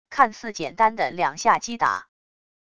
看似简单的两下击打wav音频